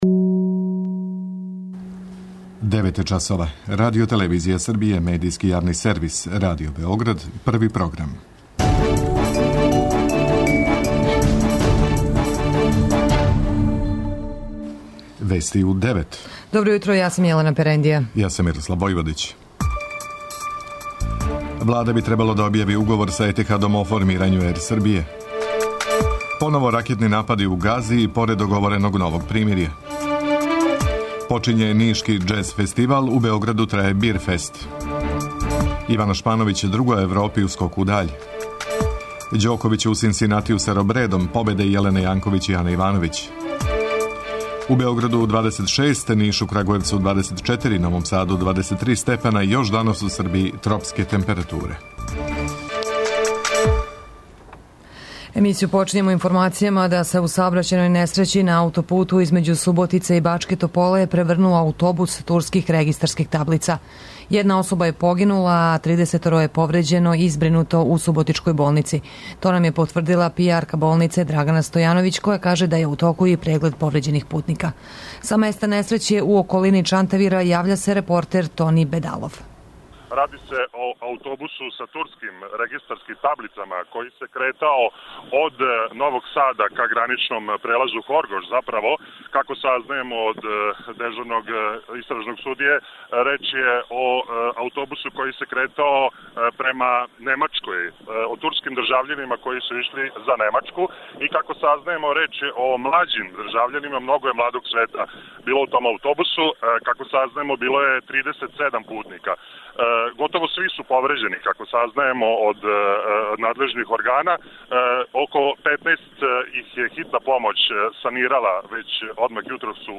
Уредници и водитељи